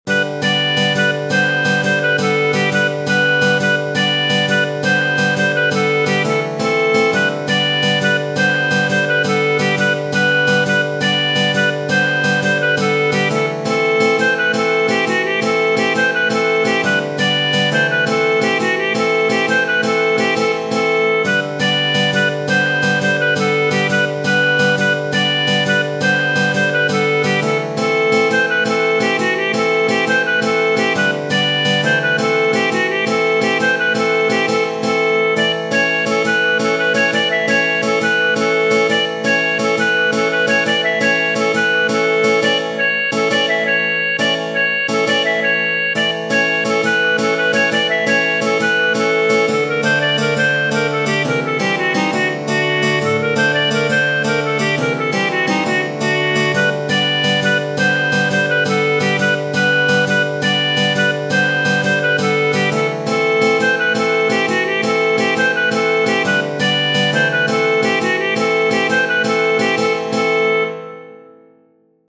Tradizionale Genere: Folk "Pajdusko oro" è una danza tradizionale dei paesi dell'area balcanica.